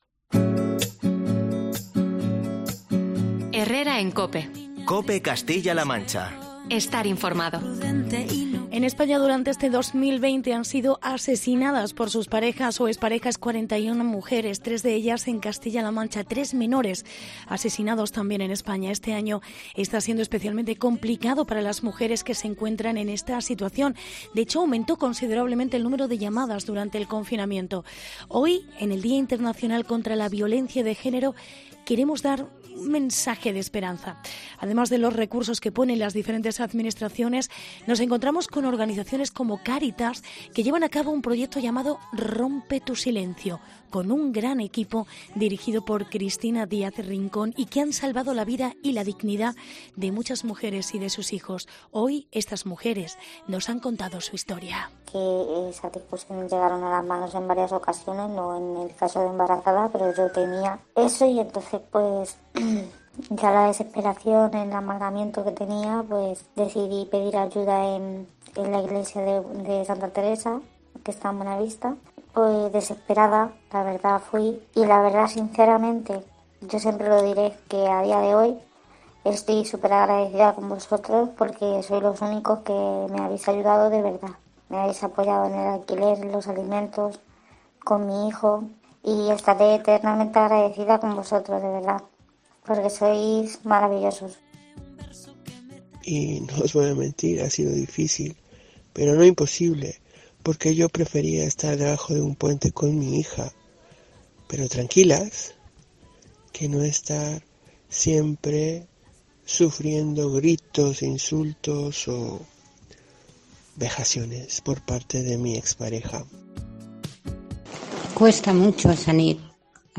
"Ha sido duro, pero se puede salir". Testimonios de mujeres que han escapado del maltrato